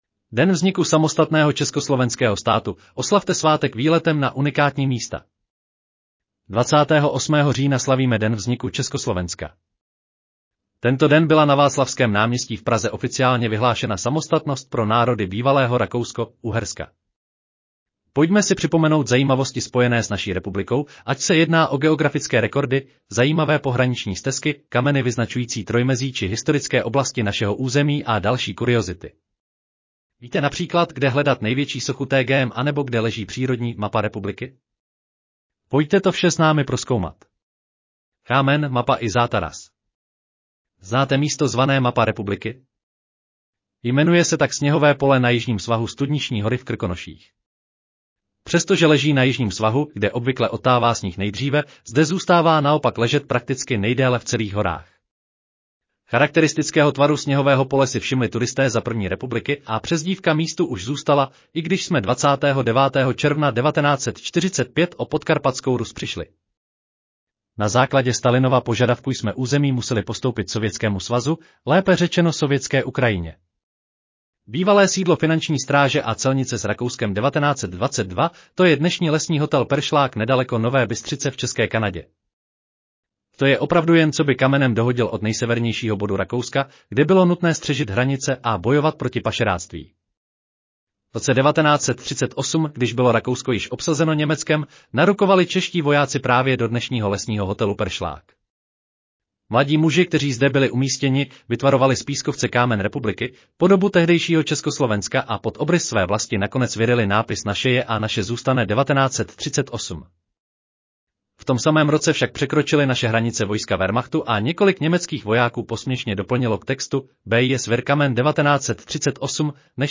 Audio verze článku Den vzniku samostatného československého státu: Oslavte svátek výletem na unikátní místa